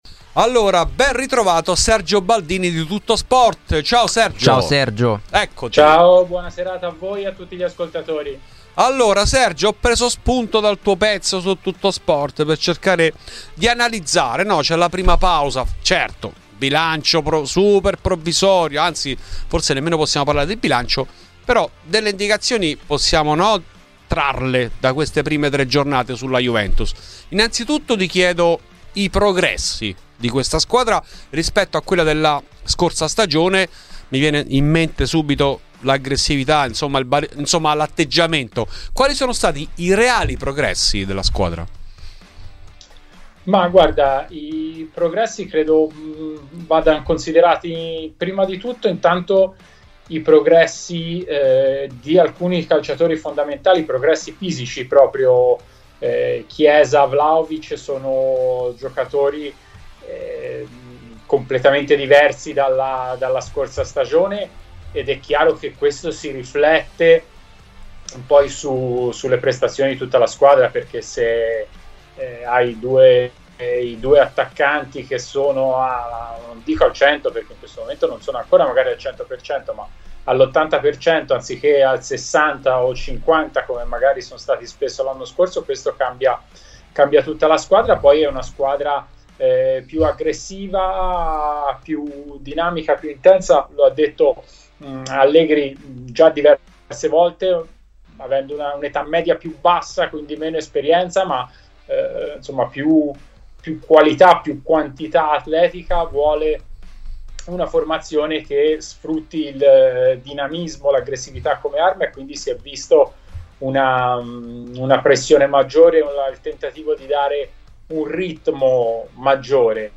Nel podcast l'intervento integrale